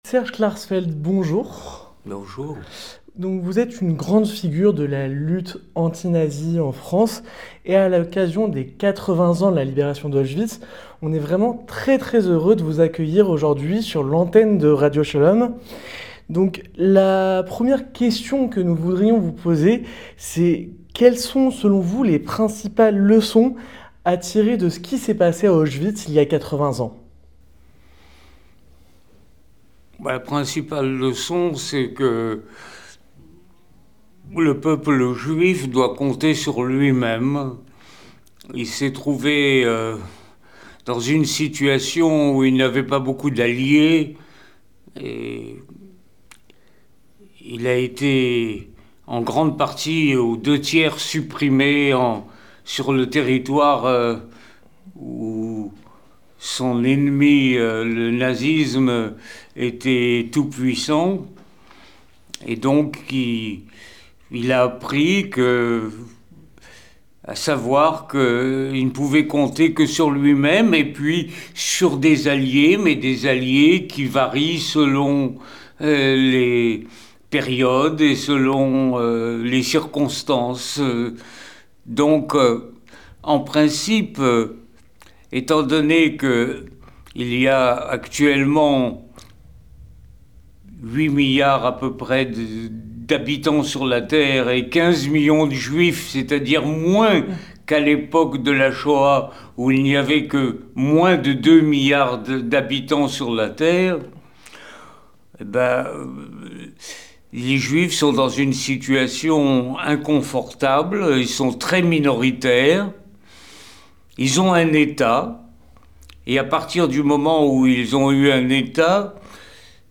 À l’occasion des 80 ans de la libération d’Auschwitz, Serge Klarsfeld est l’invité exceptionnel de Radio Shalom.